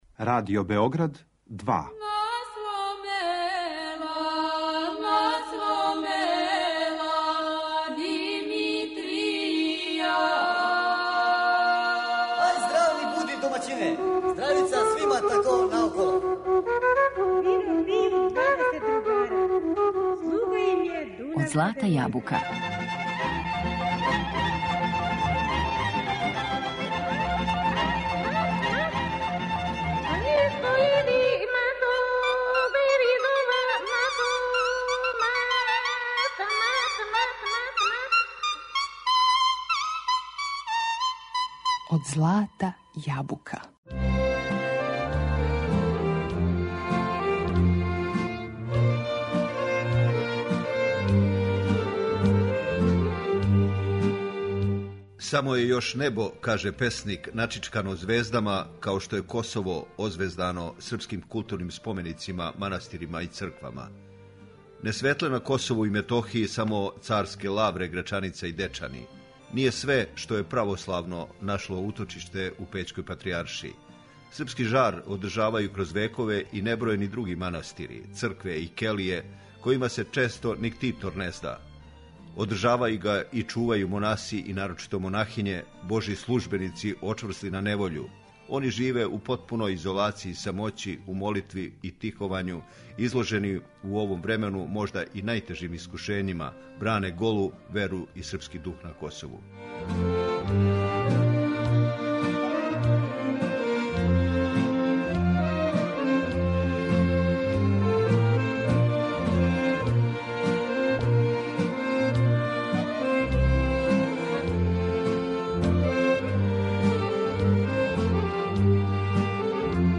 О нашим културним споменицима на простору Косова и Метохије, и данас, као и прошлог четвртка, говоримо у емисији Од злата јабука, уз најлепше косовске песмe.